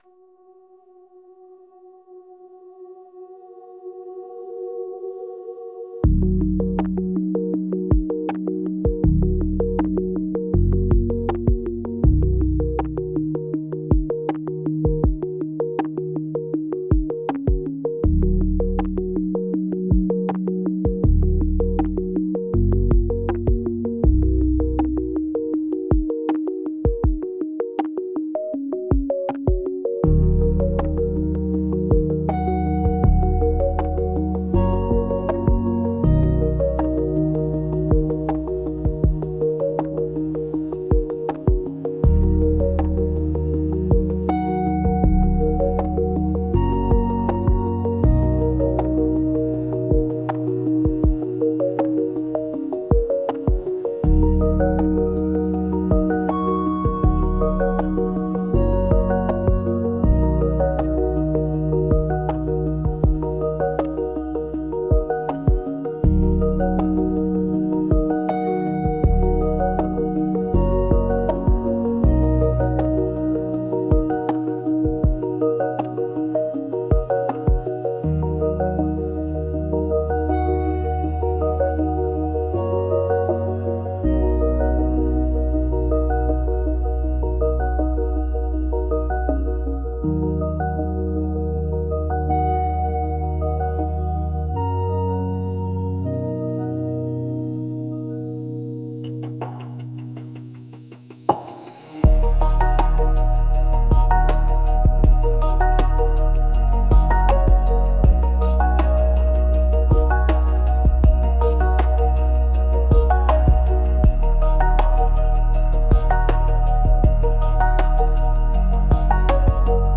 ambient-softcrunchlabs-site.wav